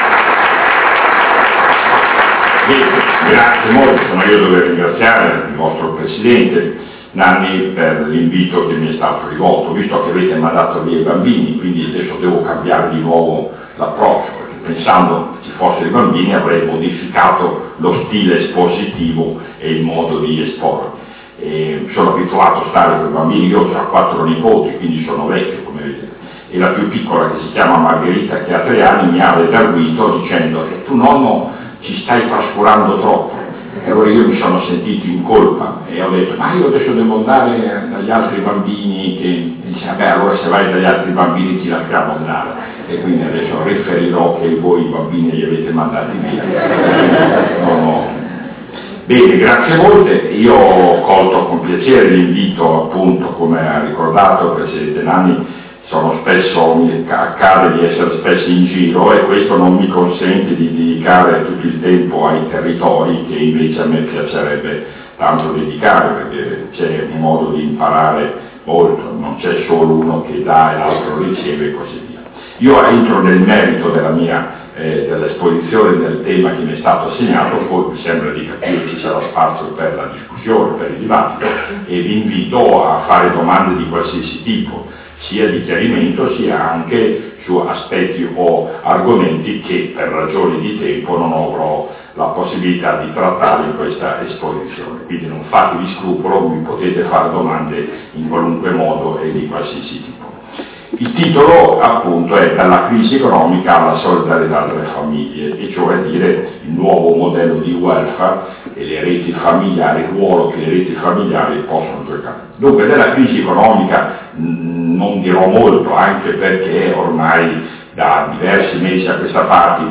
Novafeltria, 11 Ottobre 2009 VII Convegno Famiglie anno 2009
Relazione Prof. Zamagni